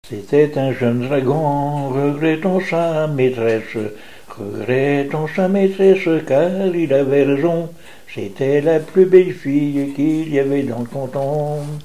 Genre strophique
Enquête Arexcpo en Vendée-Pays Sud-Vendée
Pièce musicale inédite